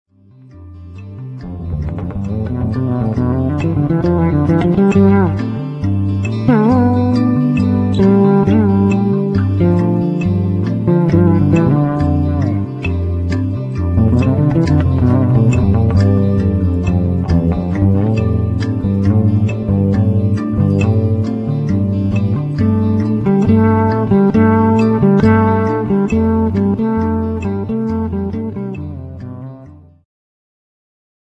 ジャンル POPS系
Progressive